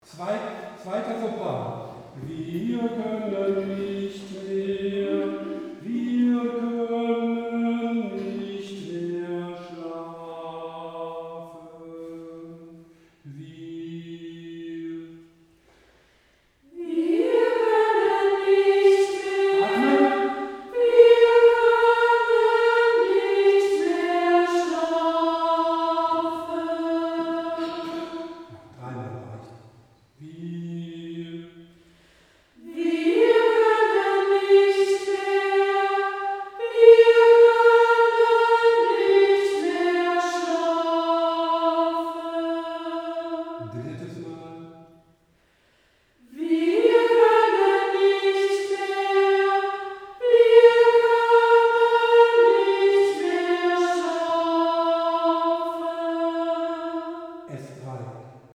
Takt 35 - 42 | Einzelstimmen
Gott in uns! | T 35 | Sopran 2